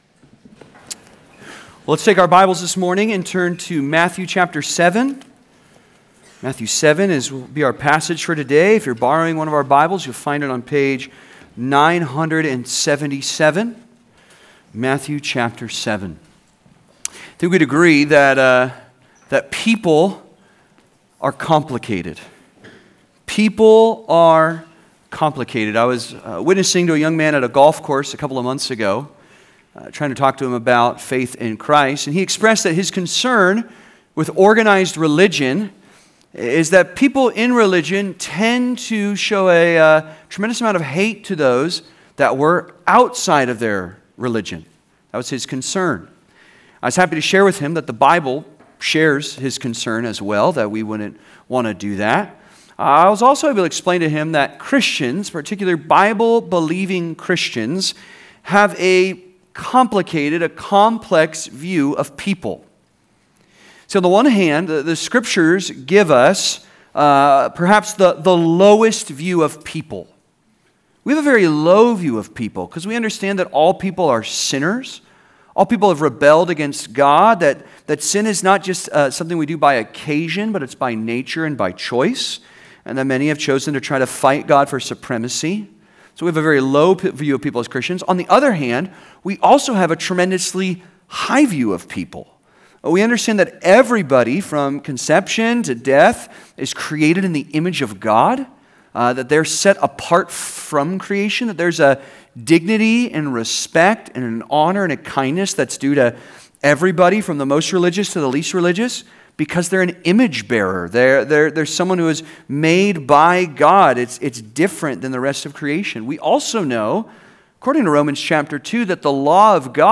The Christian's Duty To Judge and To Love (Sermon) - Compass Bible Church Long Beach